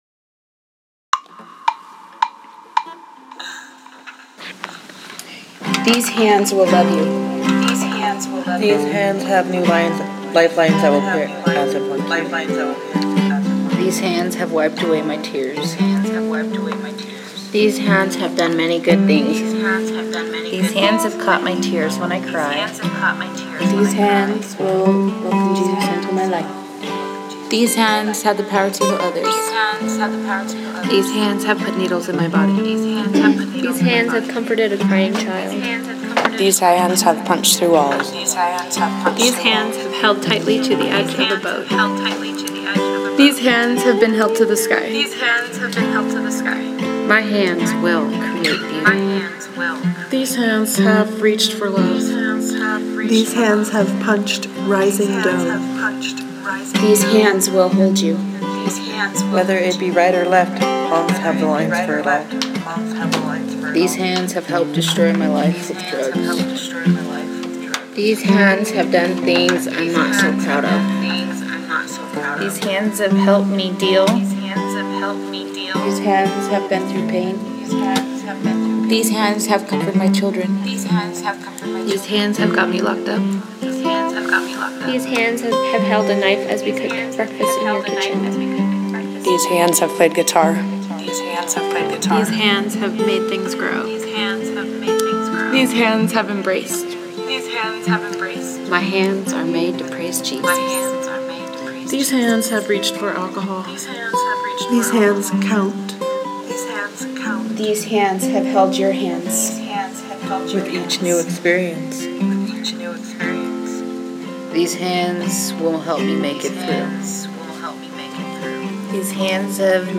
Listen to this recording from 2014 — women in the Larimer County Jail recount all the wonderful, burdening, unburdening things that hands do.